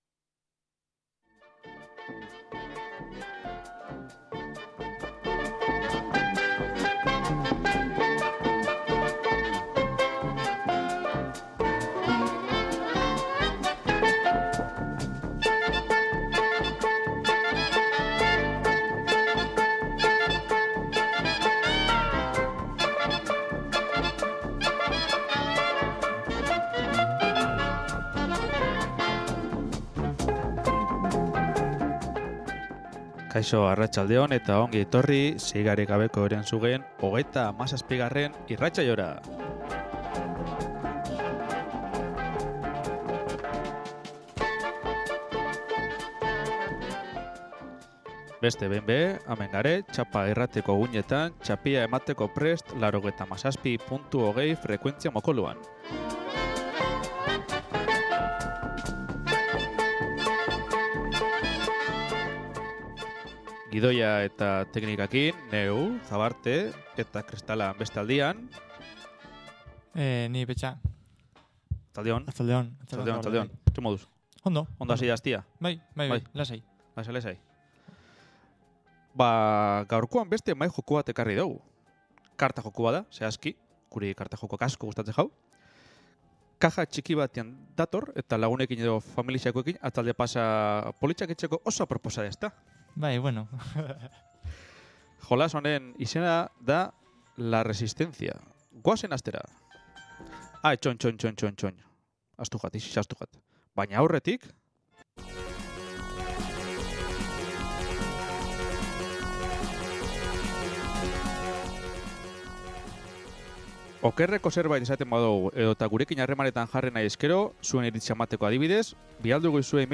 Bergara-ko Maijoku Elkartea-k mahai jokuen inguruan Txapa irratian eginiko irratsaioa. Bertan, nagusiki, mahai jokuak azalduko dira, beraien funtzionamendua, jolasteko era eta dituzten onurak aipatuz.